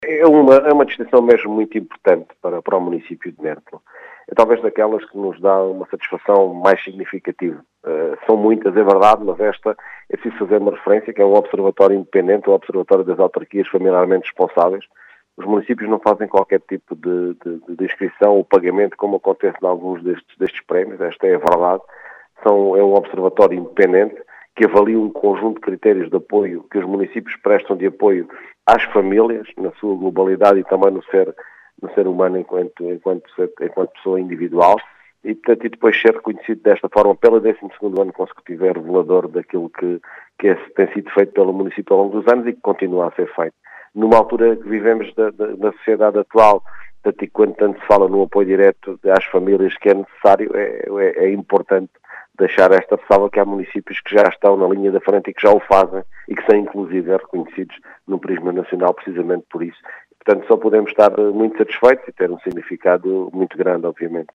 Em declarações à Rádio Vidigueira, Mário Tomé, presidente da Câmara Municipal de Mértola, diz tratar-se de uma “distinção muito importante” para o município.